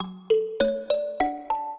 mbira